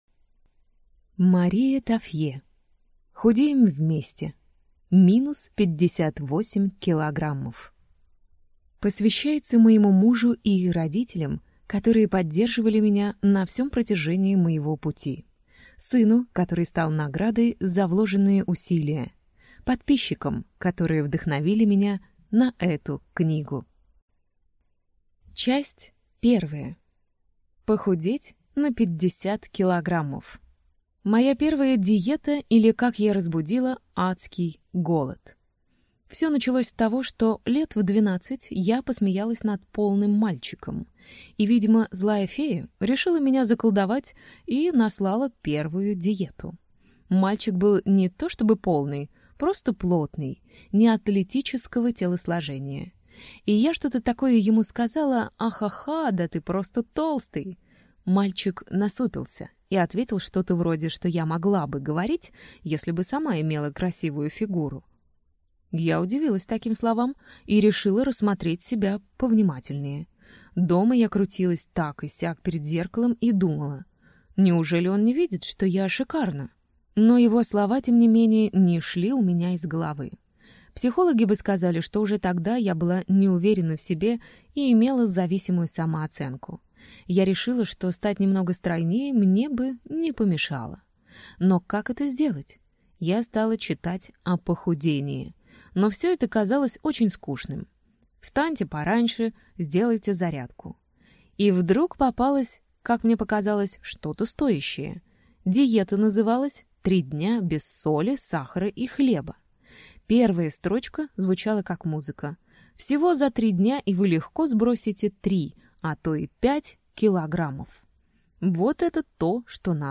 Аудиокнига Худеем вместе! Минус 58 кг | Библиотека аудиокниг